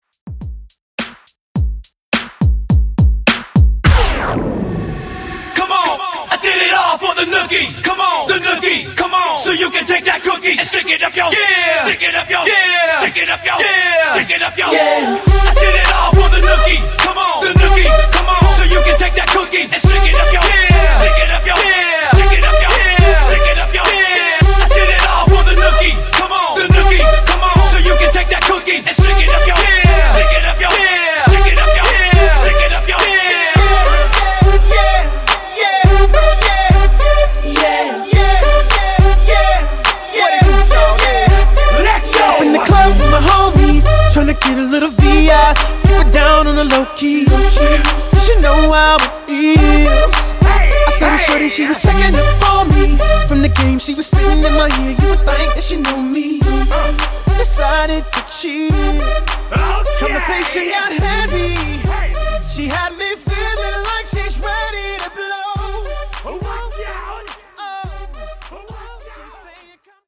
Genre: 90's
Dirty BPM: 105 Time